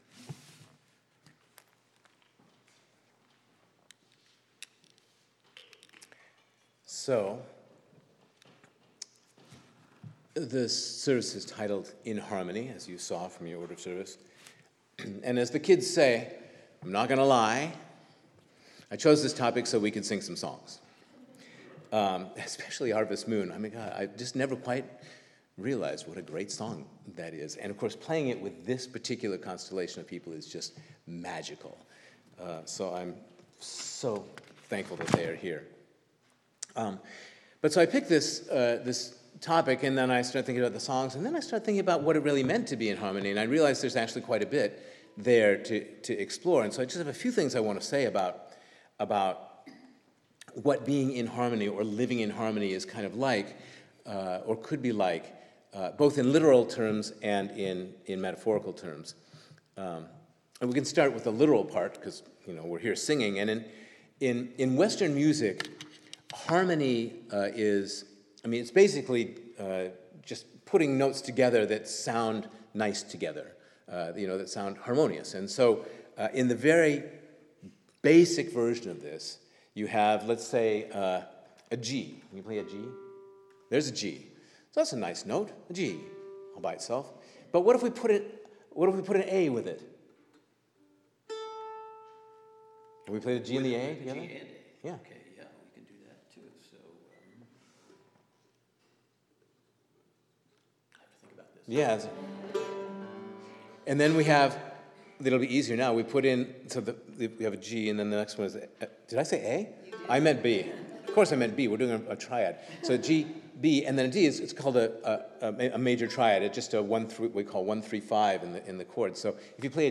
In this service our guest musicians will sing for us and provide a framework for our own harmonious living.